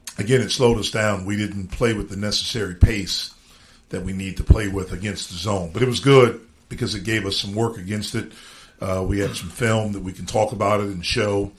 Coach Jeff Capel said the Panthers struggled early against the Bulldogs’ zone defense, but it was a good learning curve for them.